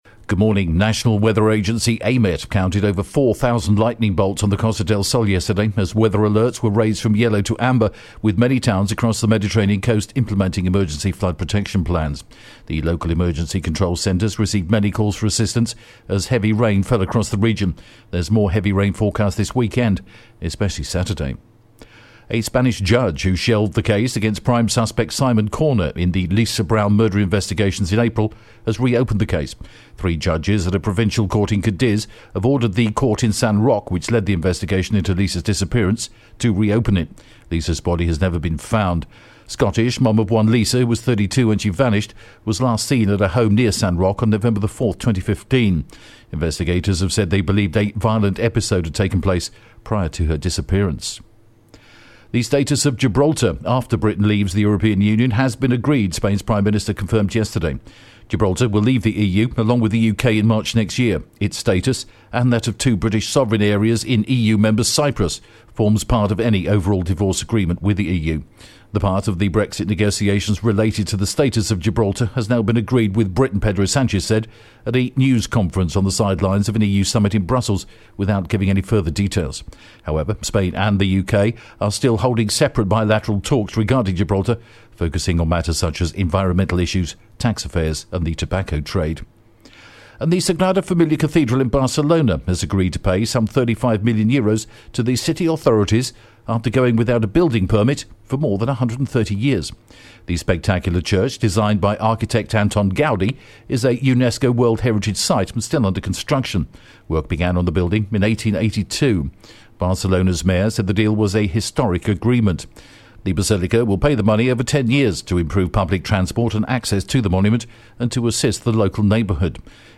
The latest Spanish News Headlines in English: October 19th am